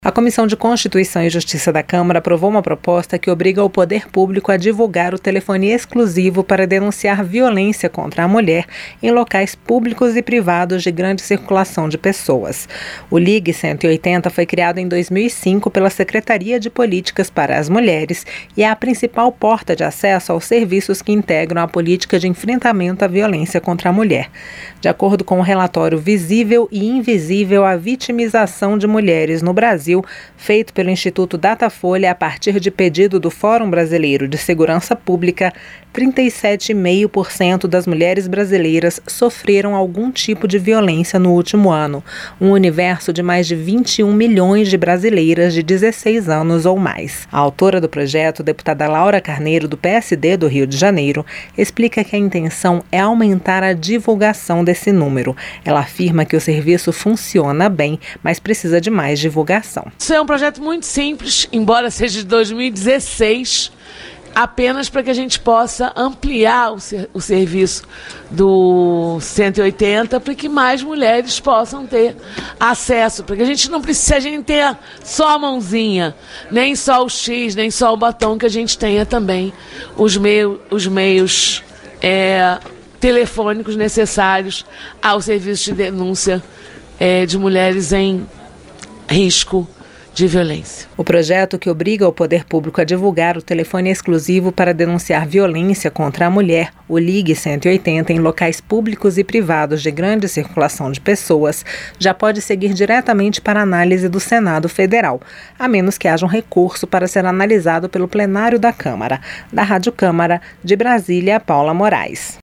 CÂMARA APROVA PROPOSTA QUE AMPLIA DIVULGAÇÃO DE TELEFONE PARA RECEBER DENÚNCIA DE VIOLÊNCIA CONTRA MULHER. A REPÓRTER